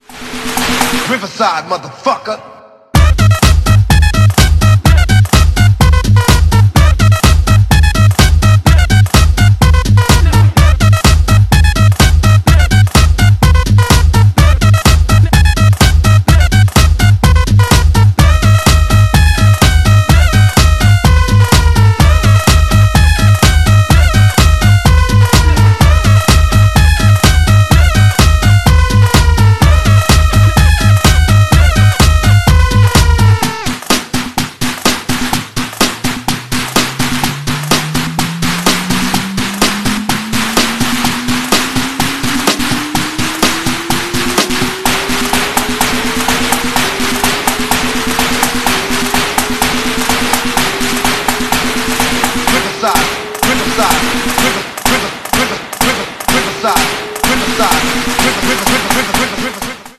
Does this 400rwk G6E turbo sound effects free download